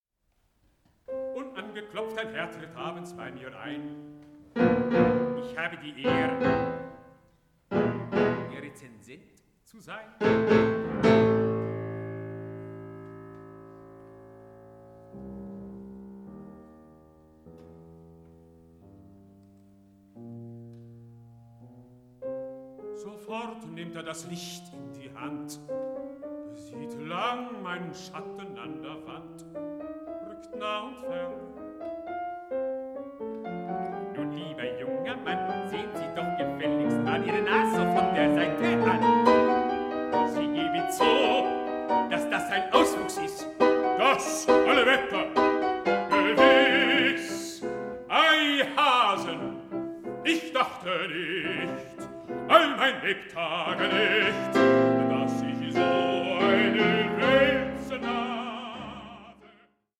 Songs to poems